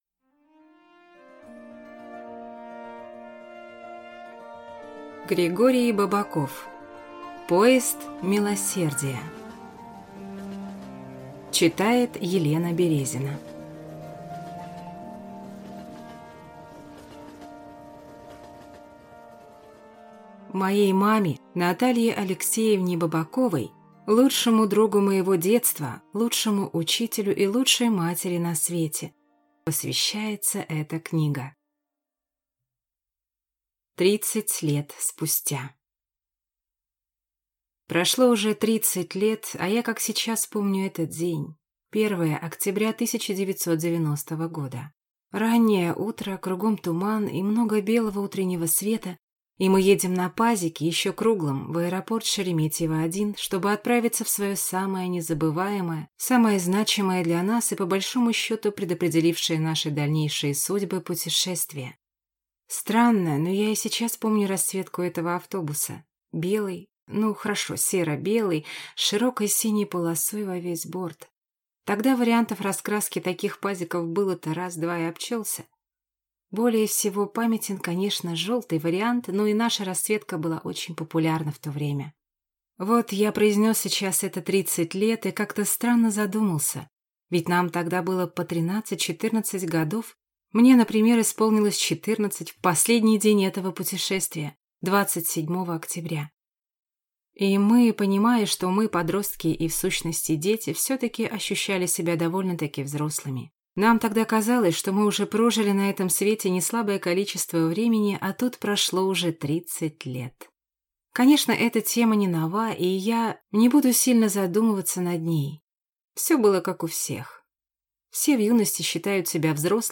Аудиокнига Поезд милосердия | Библиотека аудиокниг